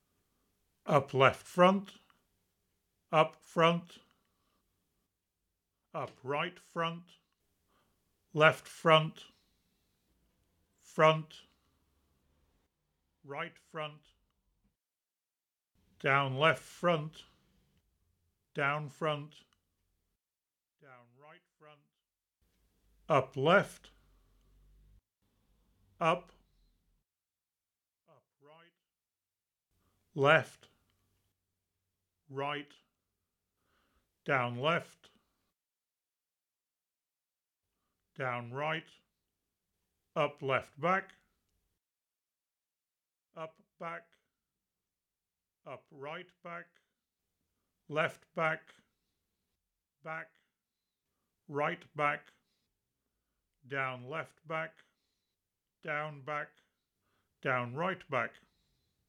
surround_test_FuMa.wav